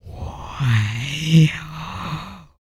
Index of /90_sSampleCDs/ILIO - Vocal Planet VOL-3 - Jazz & FX/Partition H/4 BREATH FX